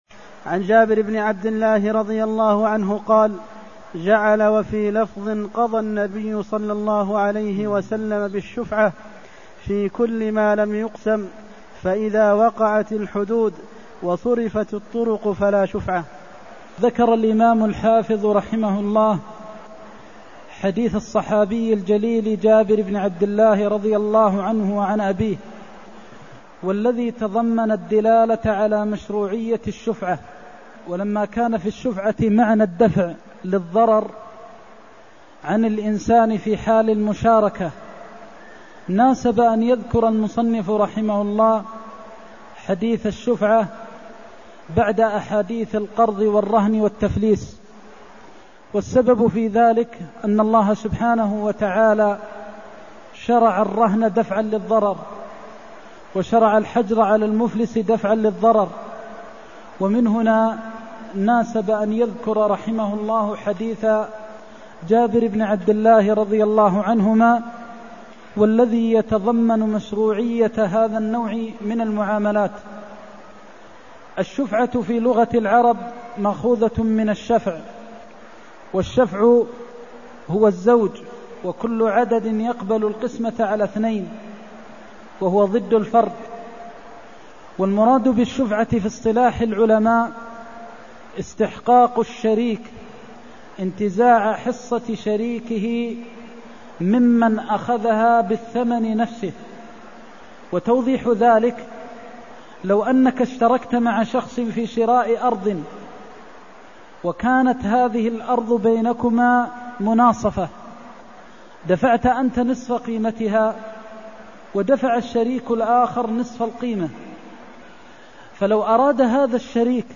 المكان: المسجد النبوي الشيخ: فضيلة الشيخ د. محمد بن محمد المختار فضيلة الشيخ د. محمد بن محمد المختار قضى النبي بالشفعة (268)